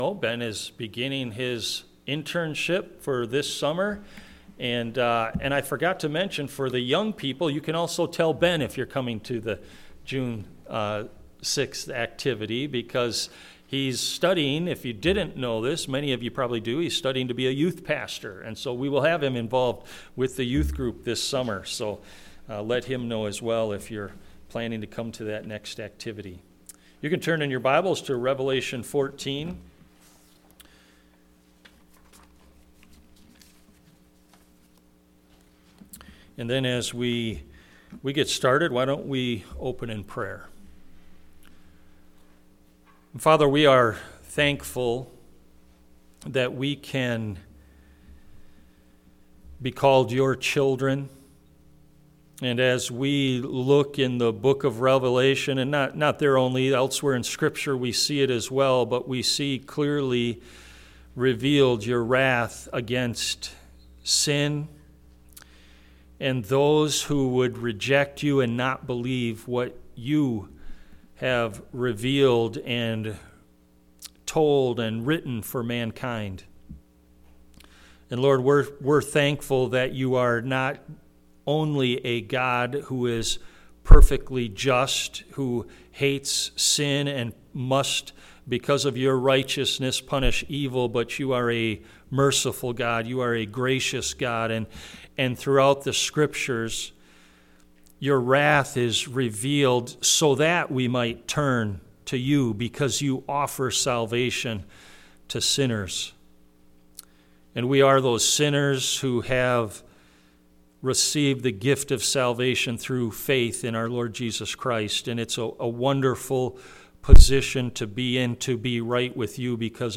All Sermons What in the World is God Doing?